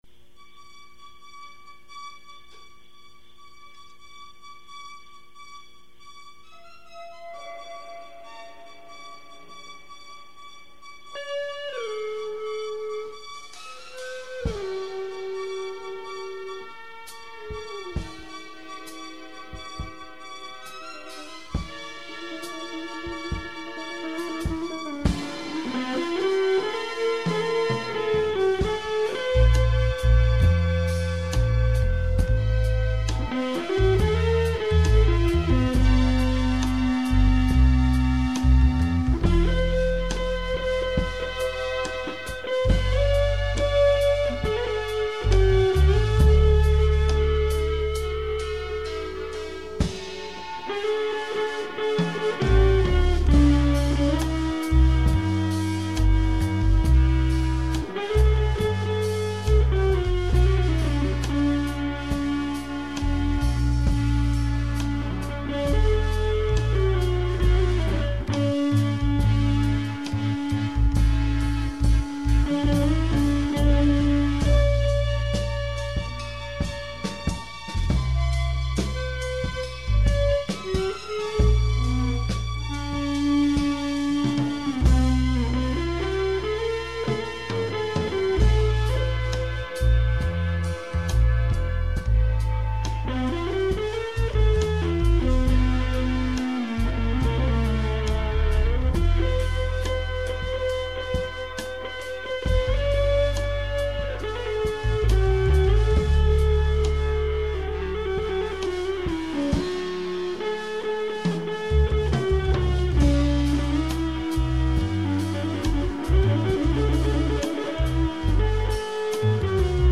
It was made by twin rackmounted yamaha fb01 sound modules.